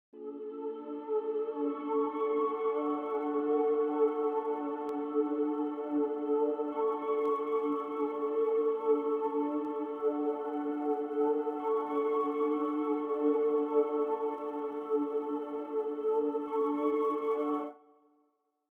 Category 🎵 Relaxation
audio chant choir Choir choir-vst choir-vst3 choral exs24 sound effect free sound royalty free Memes